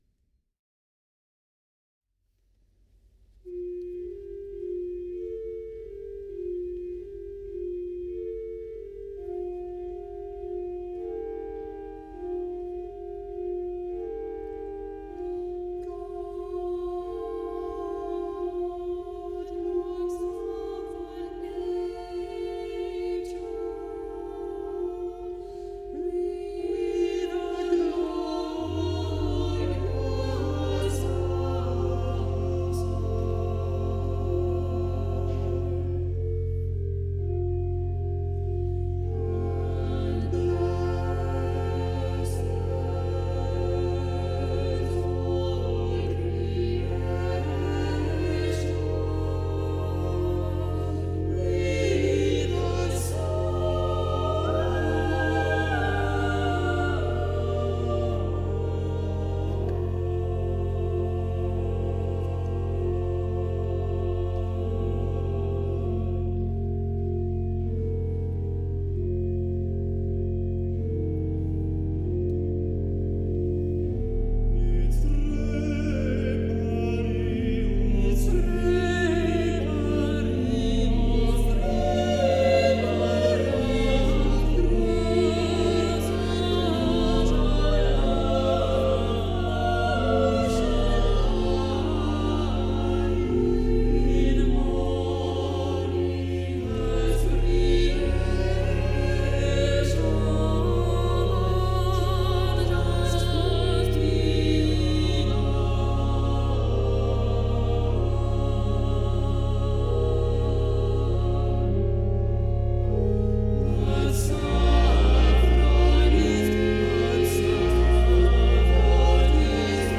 Robert Page's sacred choral compositions and arrangements.